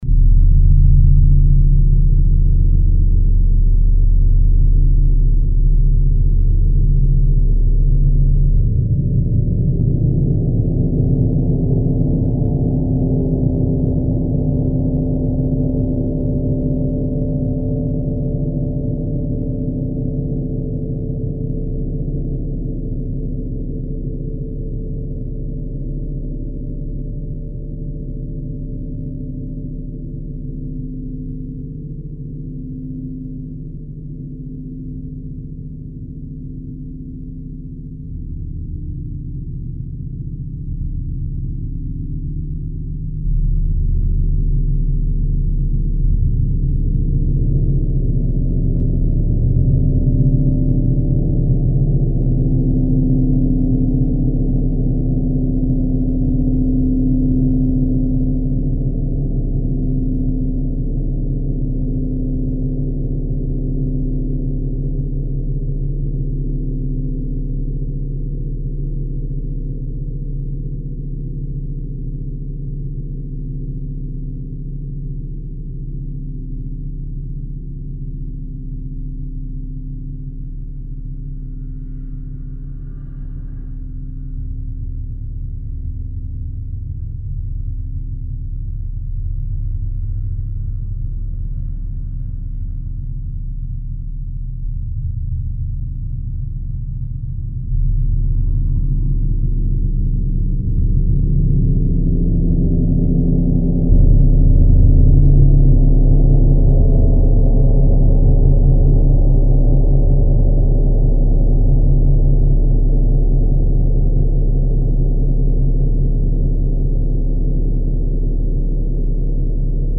Esta grabación es la real del Gong disponible
Gong Quirón 85cm